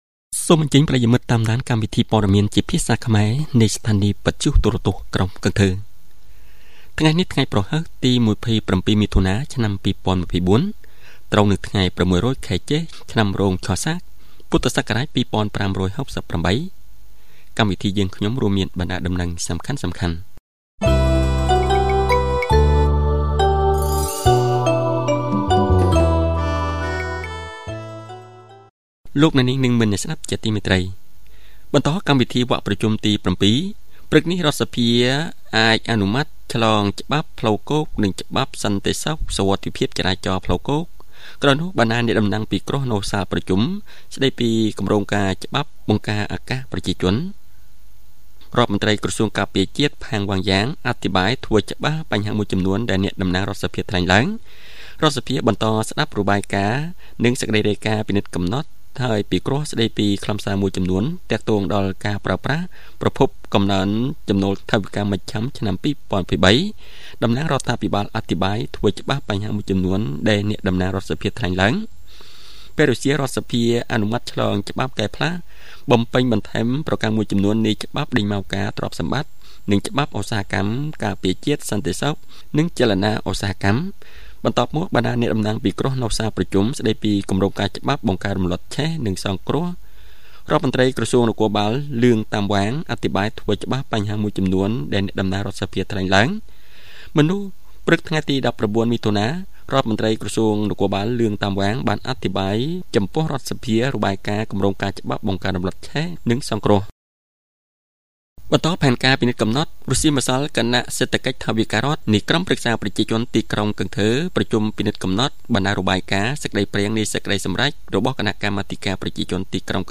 Bản tin tiếng Khmer sáng 27/6/2024